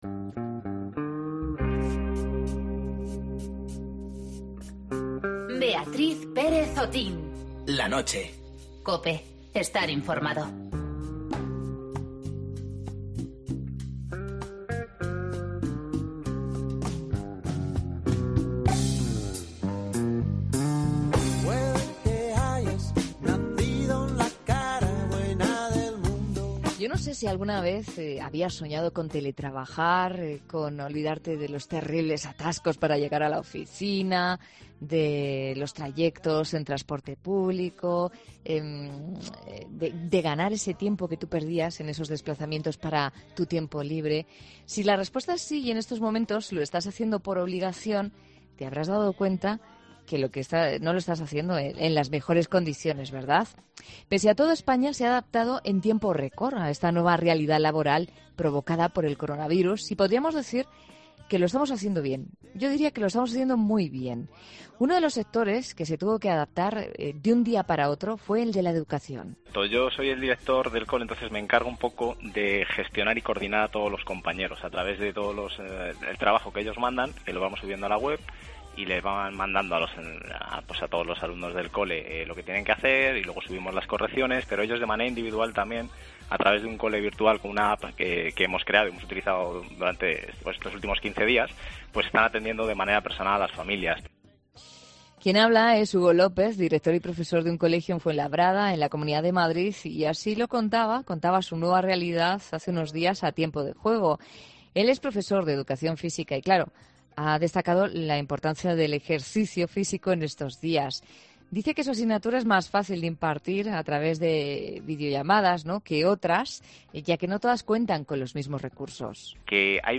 Varios expertos comparten en 'La Noche' sus conocimientos y experiencias sobre el teletrabajo